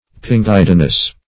Search Result for " pinguidinous" : The Collaborative International Dictionary of English v.0.48: Pinguidinous \Pin*guid"i*nous\, a. [L. pinguedo fatness, fr. pinguis fat.]